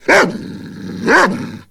58aea60d01 Divergent / mods / Soundscape Overhaul / gamedata / sounds / monsters / dog / bdog_attack_4.ogg 21 KiB (Stored with Git LFS) Raw History
bdog_attack_4.ogg